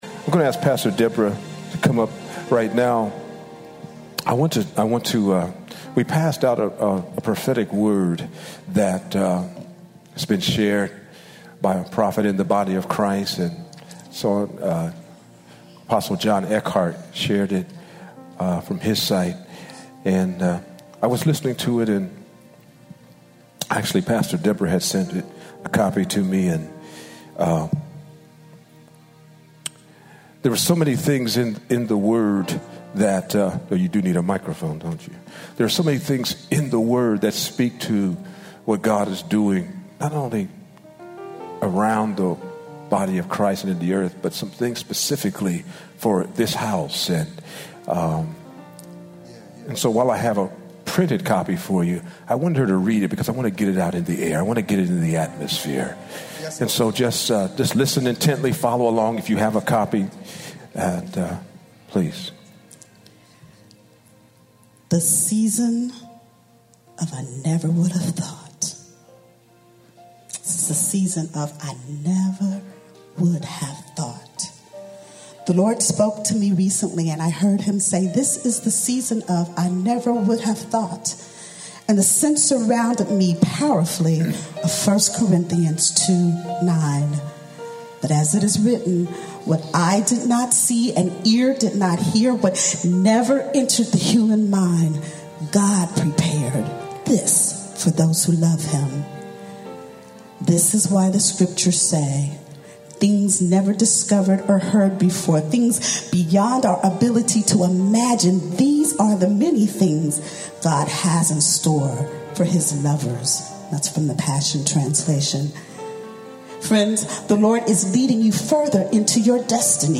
Prophetic Word